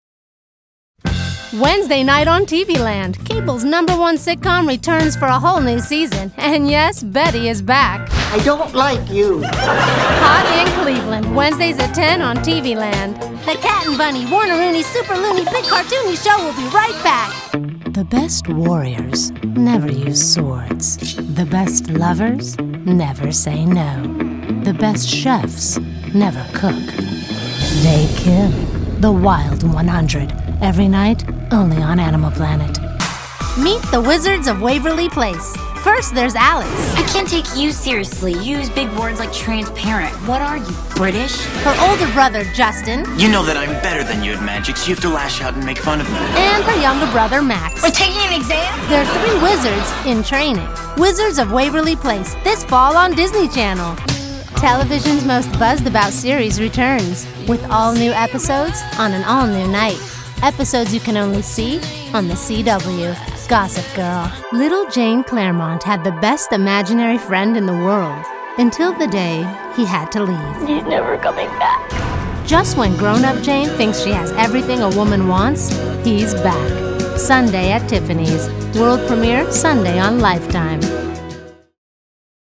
Female
Yng Adult (18-29), Adult (30-50)
Movie Trailers
Promo Demo.